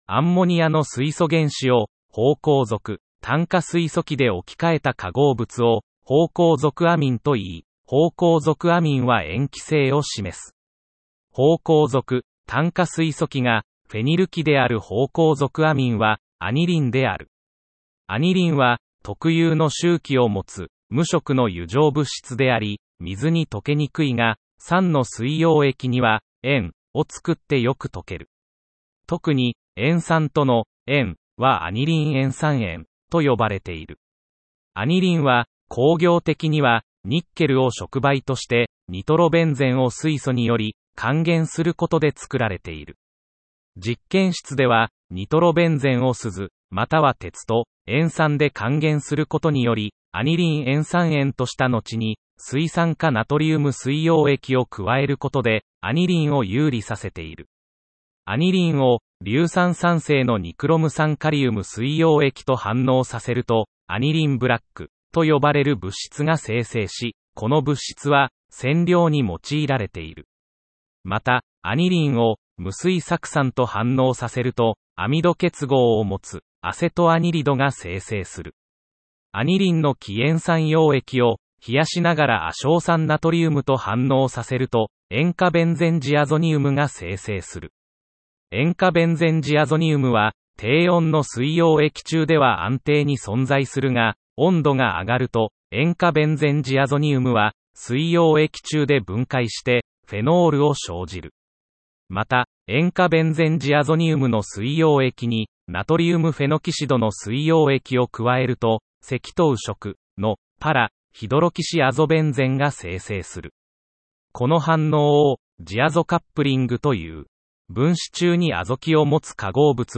問題文を朗読した音声データは『時間を有効活用したい！』という受験生のための画期的なアイテムです。
※問題文の朗読は、AIが読み上げたものを細かく調整しています。
多少、イントネーションがおかしい部分がありますが、その点はご了承ください。